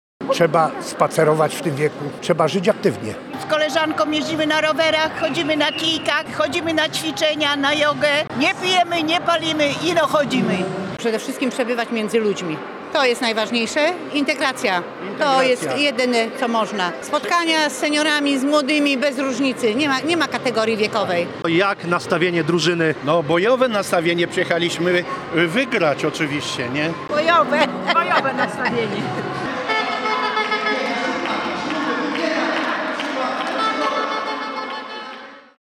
W sobotę w miejscowości Objazda położonej w gminie Ustka odbyła się pełna energii i uśmiechów Senioriada powiatu słupskiego.
Trzeba spacerować, żyć aktywnie, przede wszystkim przebywać między ludźmi, Integracja jest najważniejsza – mówili uczestnicy Senioriady.